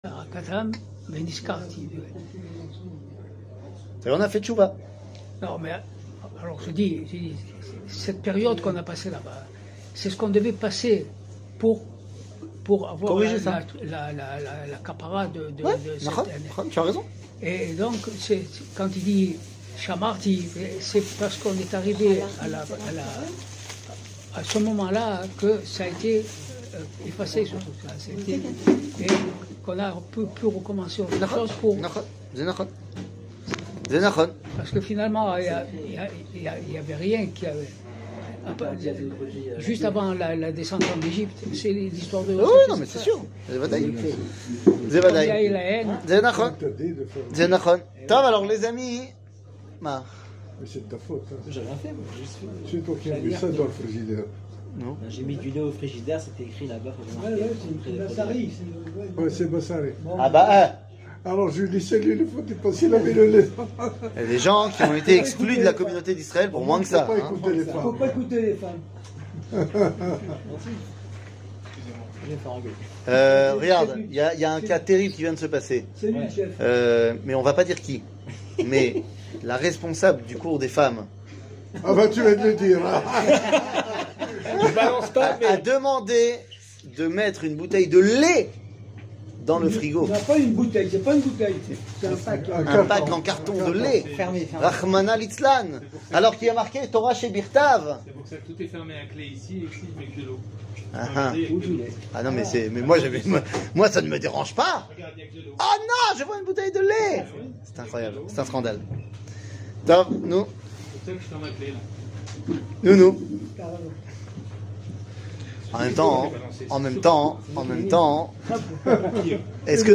שיעור מ 17 ינואר 2023 52MIN הורדה בקובץ אודיו MP3 (47.88 Mo) הורדה בקובץ וידאו MP4 (96.84 Mo) TAGS : שיעורים קצרים